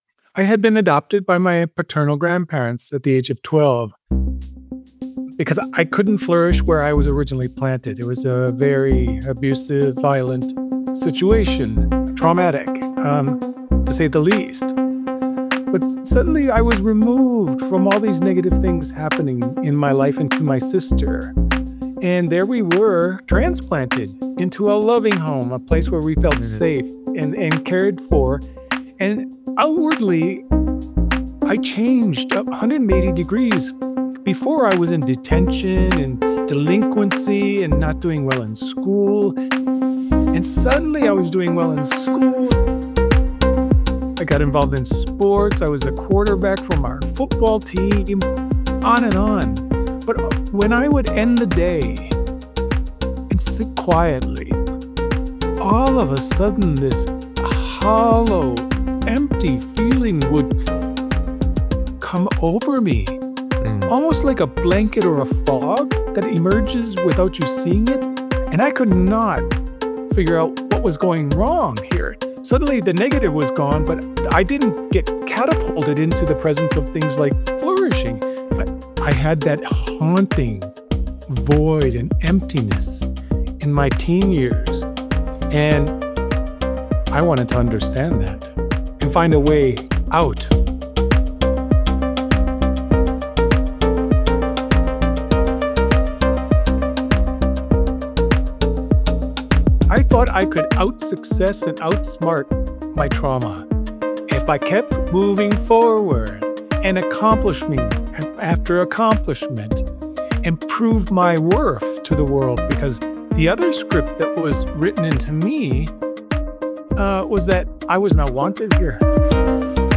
Corey Keyes is an American sociologist and the author of Languishing. Today's episode contains enhanced audio from Mo Gawdat's Slo Mo Podcast.